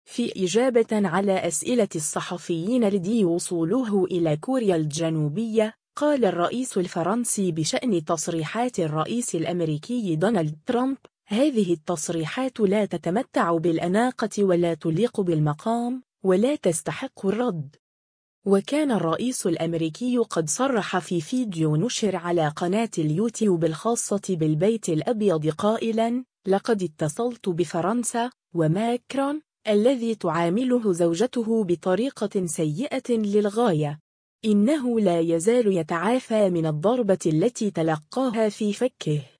في إجابةً على أسئلة الصحفيين لدى وصوله إلى كوريا الجنوبية، قال الرئيس الفرنسي بشأن تصريحات الرئيس الأمريكي دونالد ترامب: “هذه التصريحات لا تتمتع بالأناقة ولا تليق بالمقام، ولا تستحق الرد”.